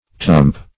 Tump \Tump\ (t[u^]mp), n. [W. twmp, twm, a round mass or heap, a